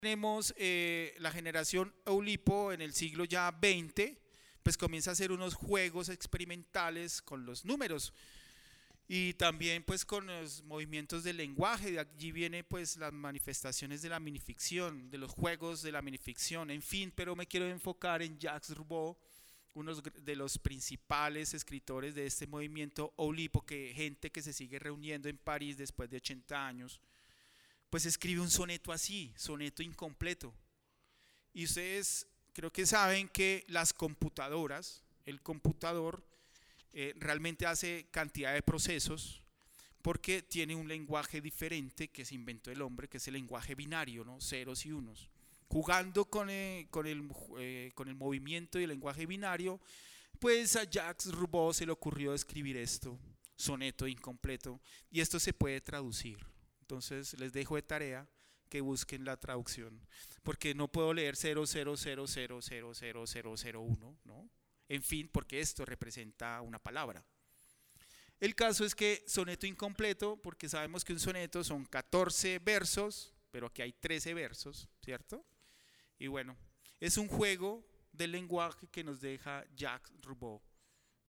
conversaron acerca de la relación entre poesía y ciencia en la conferencia Estado Sólido: Poesía y Ciencia, en el segundo día de actividades de la Feria Internacional del libro de Guadalajara 2022.